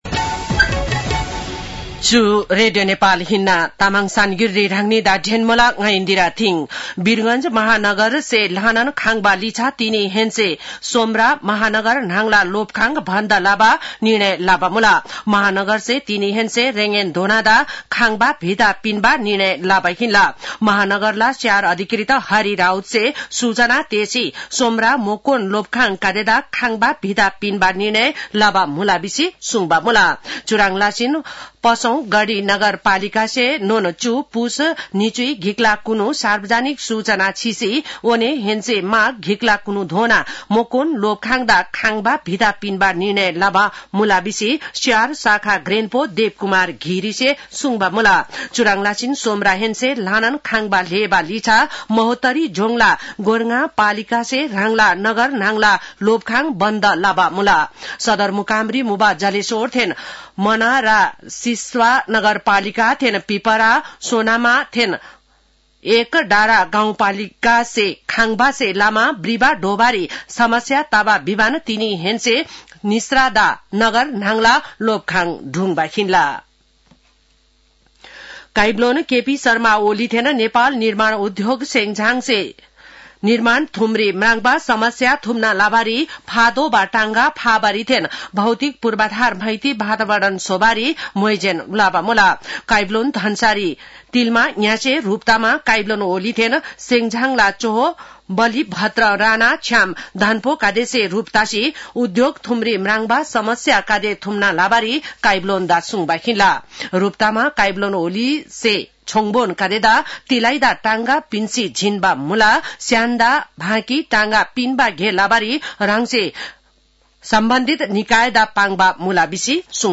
तामाङ भाषाको समाचार : २५ पुष , २०८१
Tamang-news-9-24.mp3